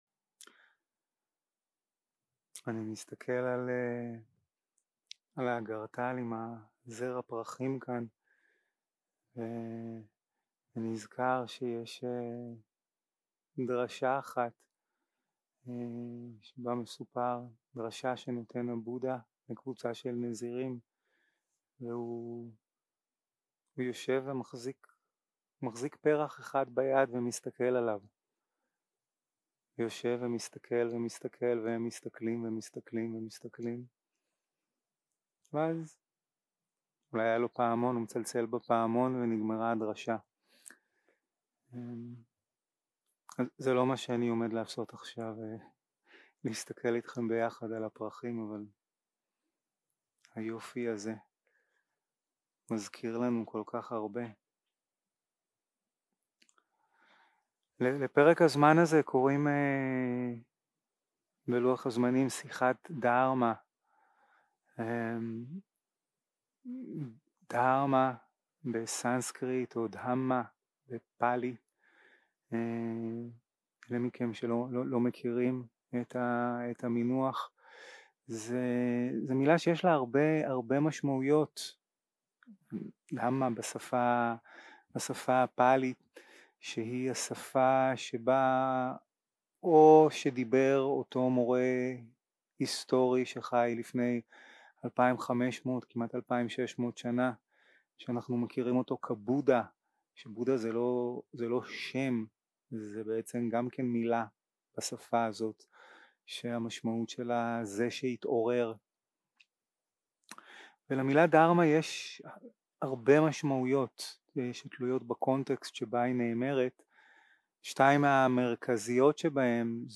יום 2 - הקלטה 4 - ערב - שיחת דהרמה - לדעת דוקהה, להרפות היאחזות 0:00 0:00 סוג ההקלטה: Dharma type: Dharma Talks שפת ההקלטה: Dharma talk language: Hebrew